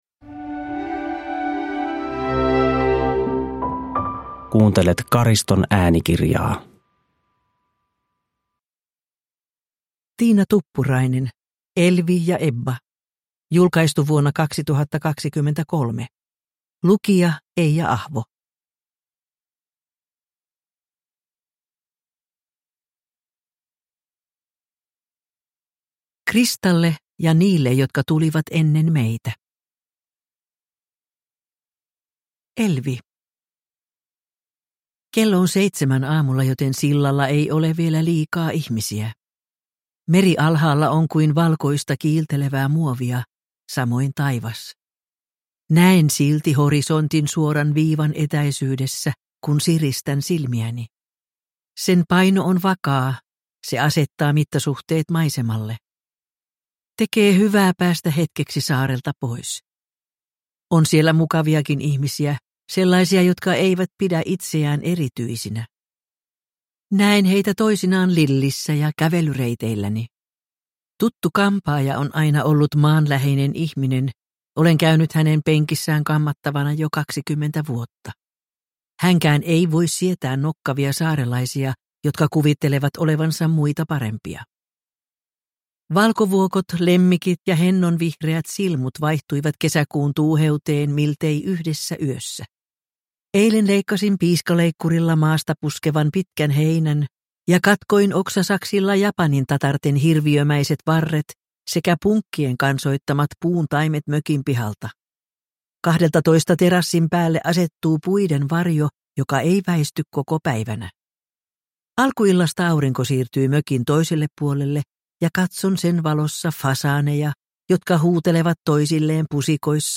Elvi ja Ebba – Ljudbok – Laddas ner